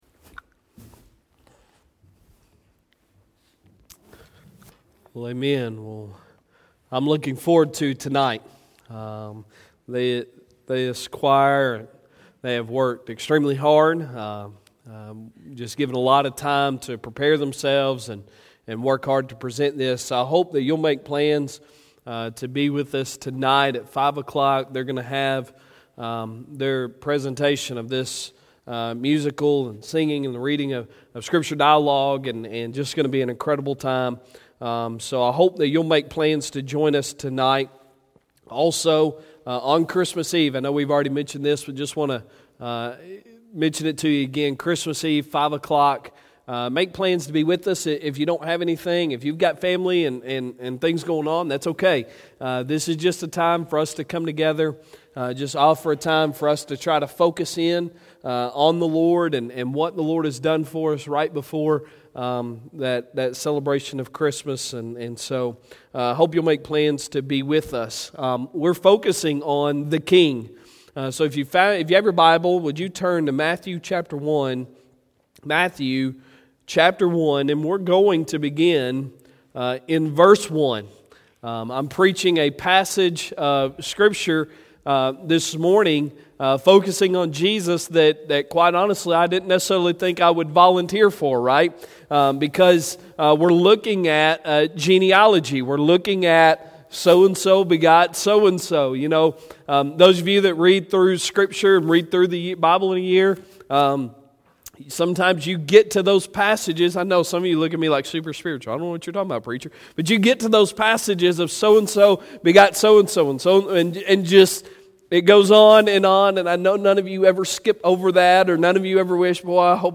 Sunday Sermon December 22, 2019